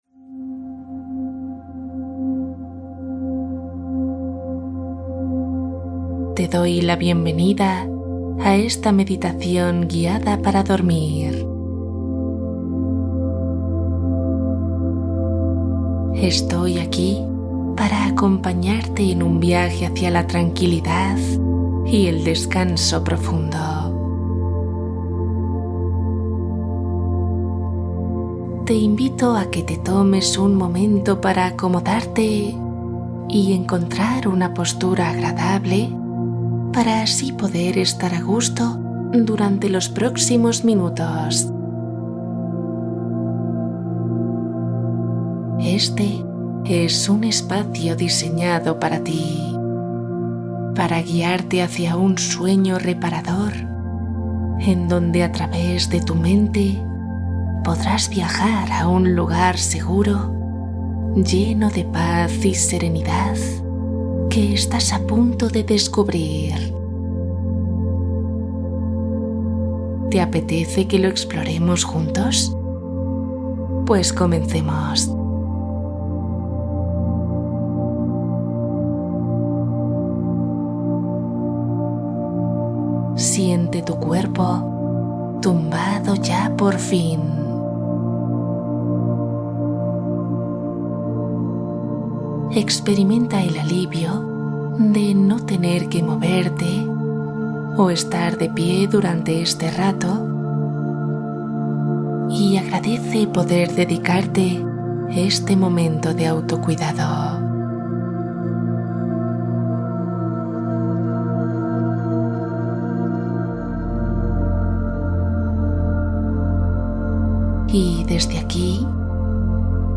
Descansa y duerme Meditación guiada para calmar tu mente